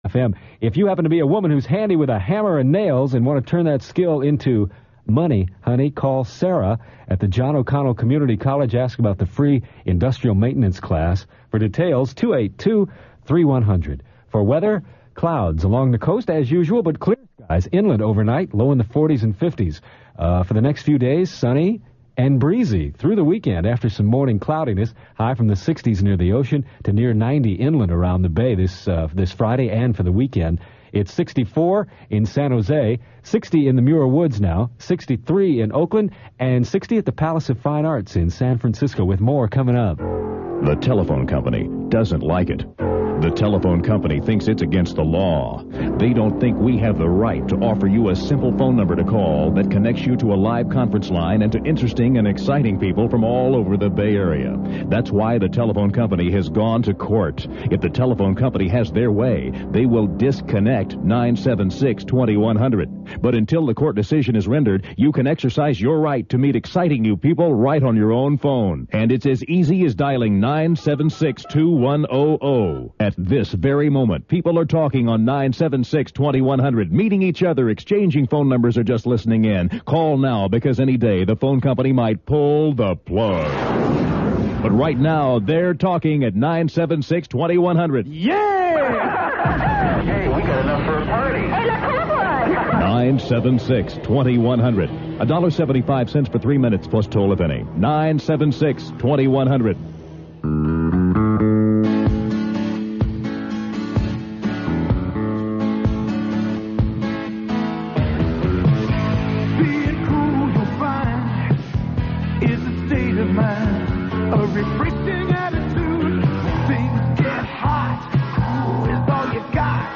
Aircheck: KLOK-FM to KKSF